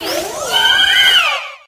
Audio / SE / Cries / FLORGES.ogg